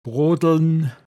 Wortlisten - Pinzgauer Mundart Lexikon
Zeit verschwenden brodln